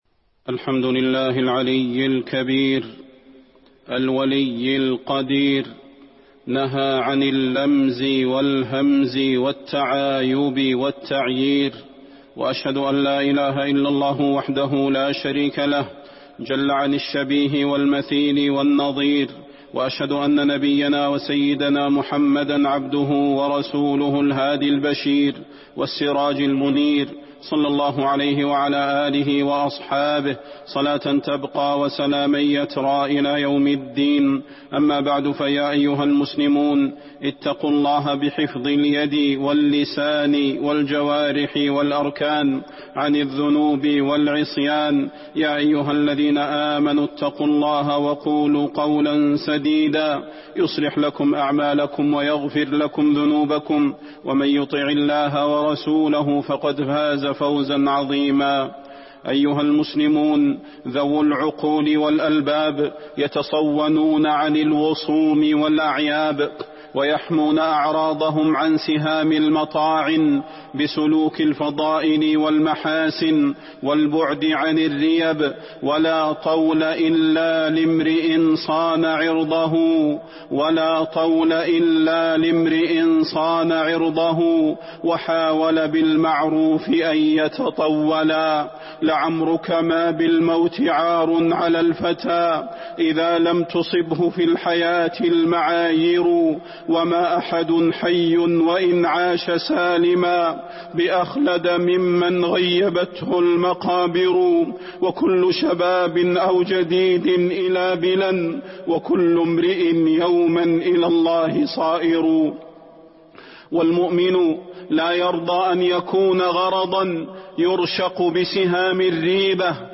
فضيلة الشيخ د. صلاح بن محمد البدير
تاريخ النشر ١٧ جمادى الأولى ١٤٤٢ هـ المكان: المسجد النبوي الشيخ: فضيلة الشيخ د. صلاح بن محمد البدير فضيلة الشيخ د. صلاح بن محمد البدير التحذير من الانشغال بعيوب الناس